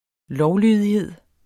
Udtale [ -ˌlyˀðiˌheðˀ ]